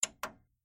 Звуки CD-плеера
Звук нажатия кнопки CD-проигрывателя